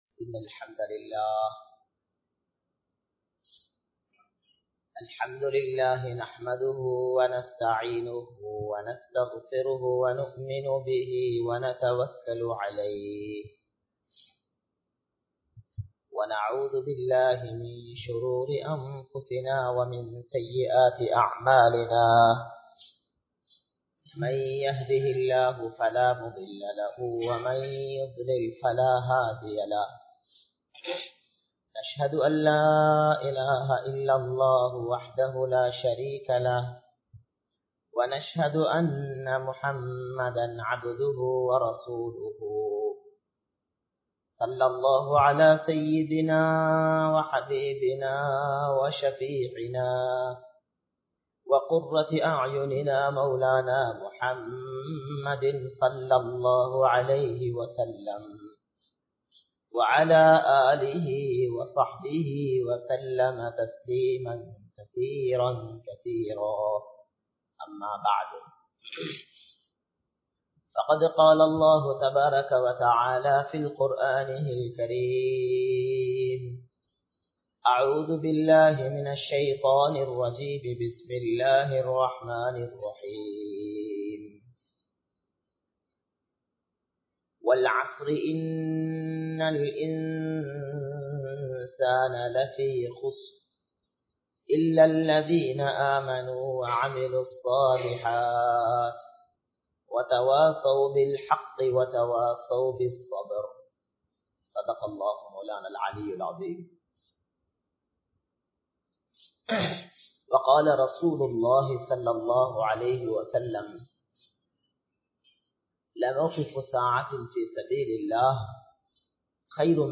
Dhauwaththudaiya Ulaippin Sirappu (தஃவத்துடைய உழைப்பின் சிறப்பு) | Audio Bayans | All Ceylon Muslim Youth Community | Addalaichenai